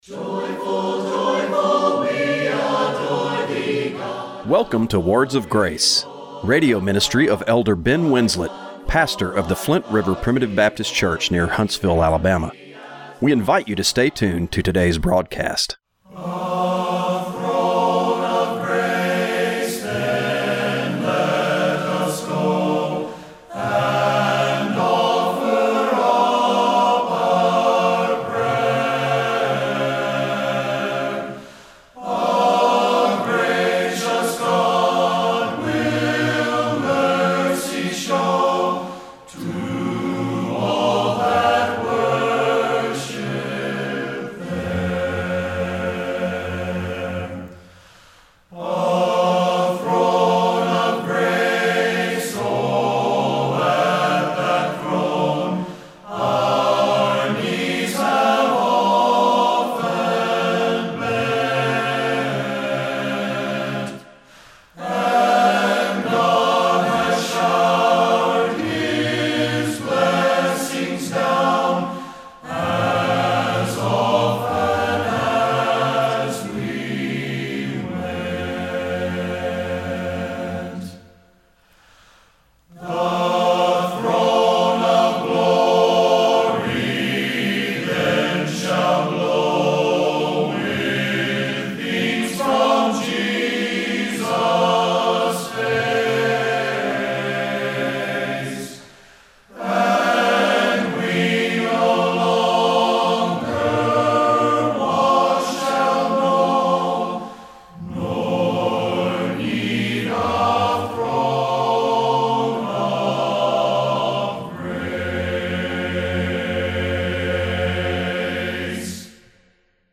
Radio broadcast for November 3, 2024.